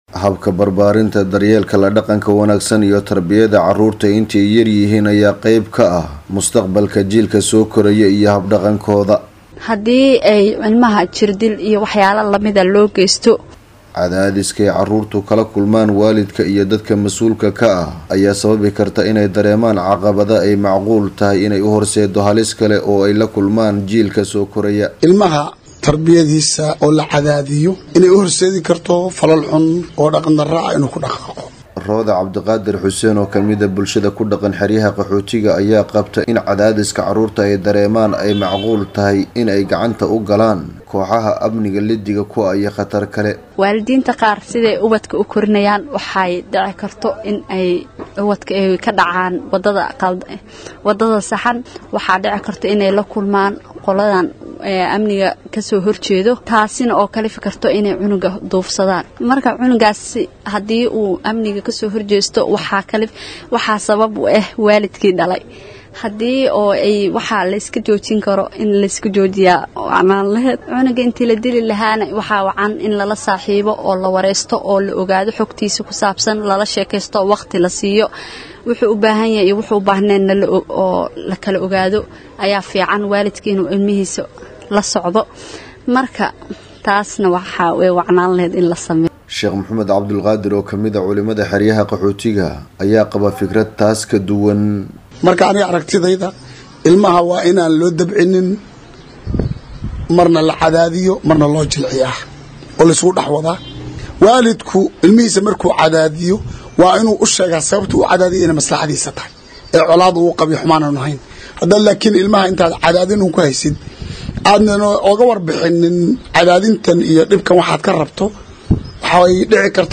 DHAGEYSO:Warbixin ku saabsan saameynta mustaqbalka ee habka tarbiyeynta ubadka